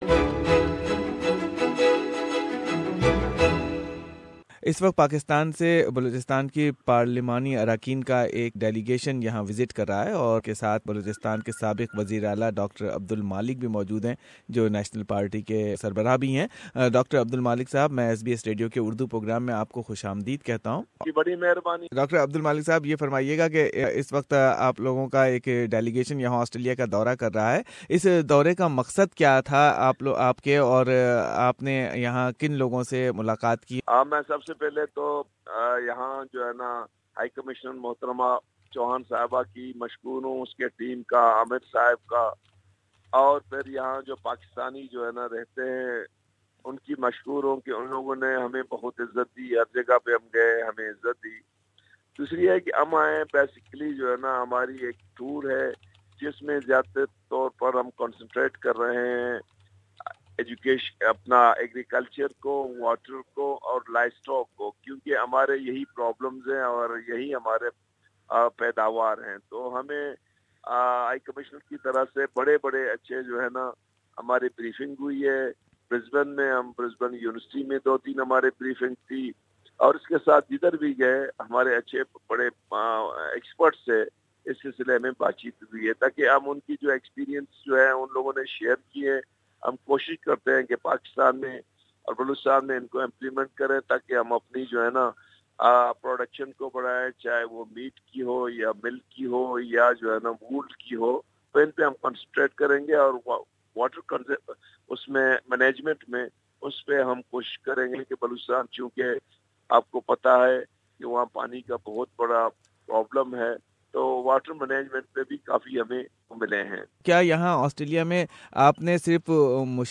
A Parliamentary delegation from Baluchistan is visiting Australia to explore the opportunity of cooperation besides observing the Australian water system, agriculture and other common sharing areas of interest. Former chief minister of Baluchistan Dr Abdul Malik is head of National party that has alliance with ruling PML-N. Dr Abdul Malik is taking about various aspects of cooperation between the two countries. He also shared his views about terrorism, dialogue with separatist elements, development and CEPAC, and his concern about minorities of Baluchistan.